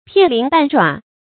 片鱗半爪 注音： ㄆㄧㄢˋ ㄌㄧㄣˊ ㄅㄢˋ ㄓㄠˇ 讀音讀法： 意思解釋： 喻事物的極小部分。